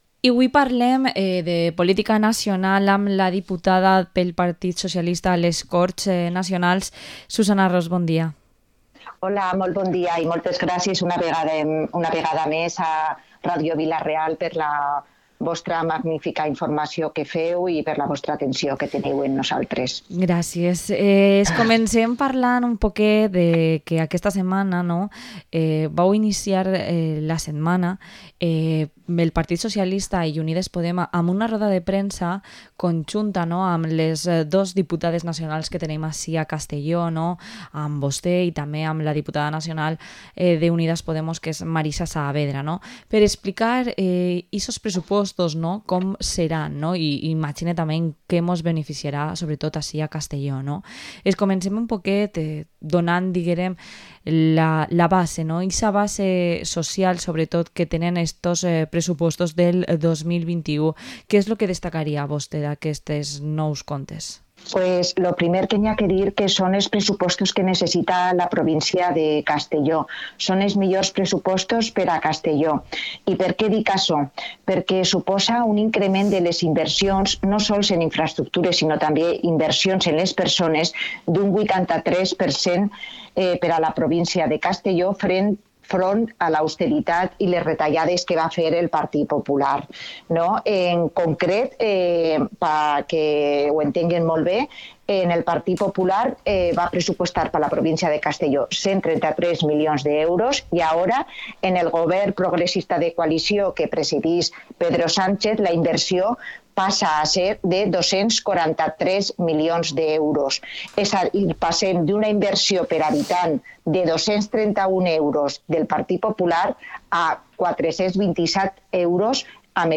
Entrevista a la diputada nacional del PSOE, Susana Ros